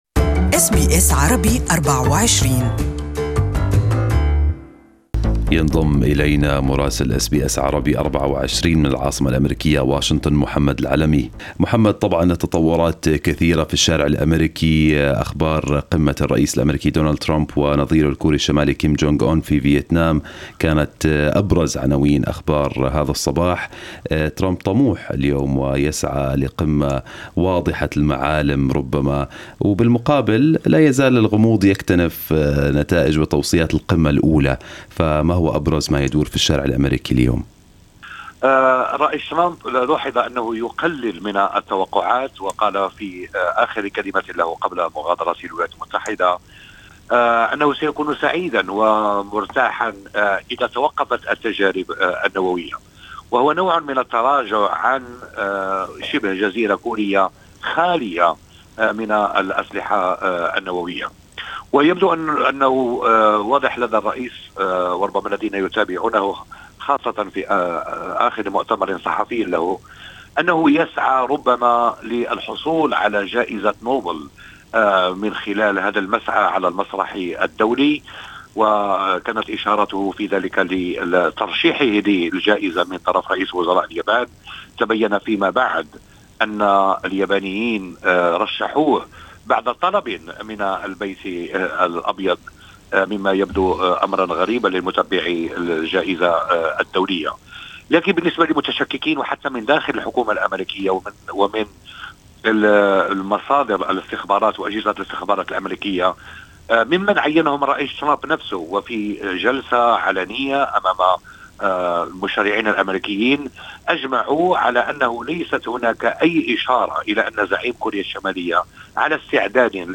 Listen to the full report from Washington in Arabic above.